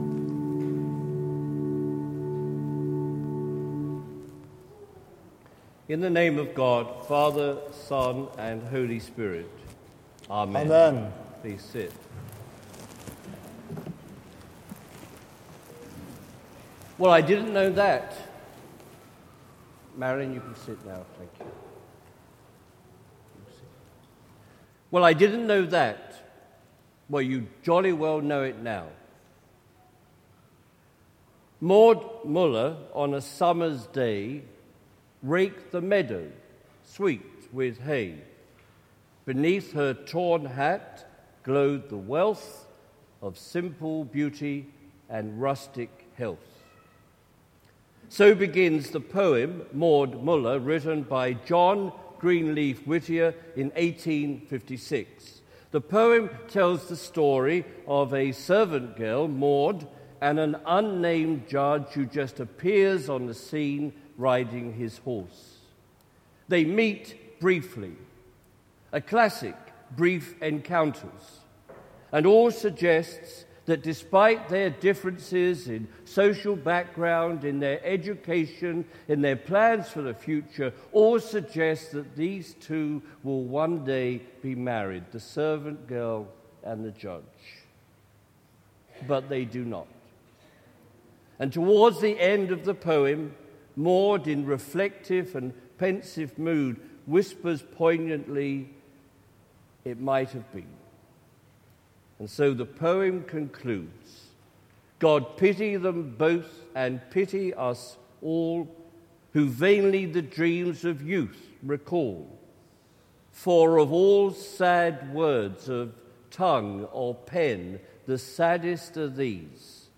Sermon “Well you know now”